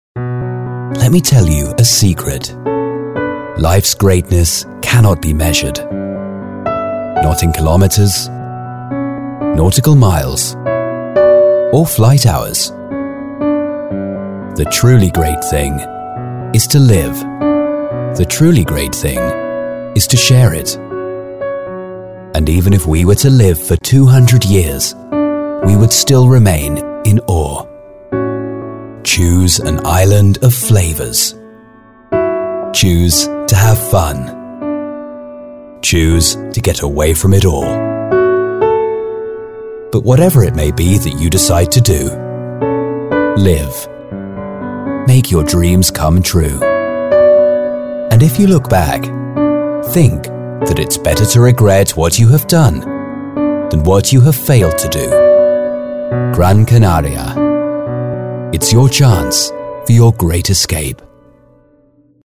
Meine Stimme kann warm, beruhigend, bestimmt oder energetisch wirken, und ich spreche ein klassisches, akzentfreies britisch Englisch.
Ein erfahrener englischer Sprecher mit einer warmen, dynamischen Stimme!
Sprechprobe: Sonstiges (Muttersprache):
An experienced VO talent, with a warm and dynamic voice.